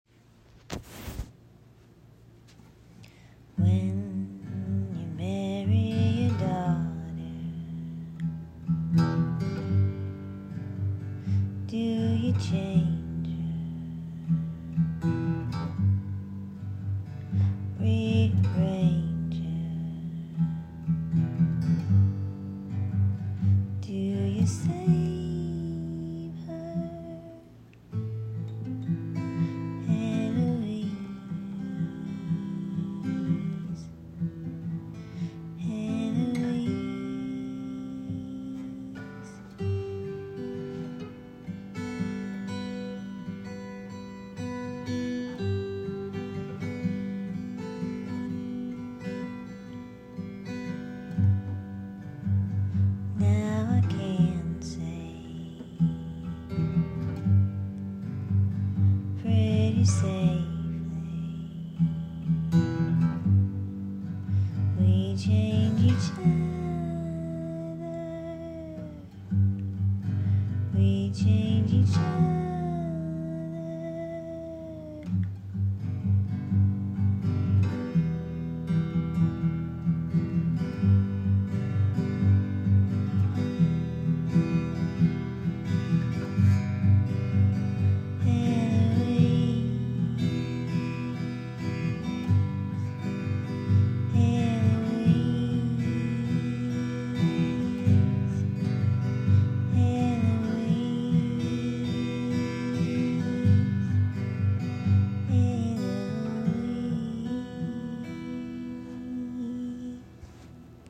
iphone recording, written oct 2023. when you marry a daughter, do you change her, do you save her? rearrange her? eloise, eloise. now i can say, pretty safely, we change each other. we change each other. eloise. eloise.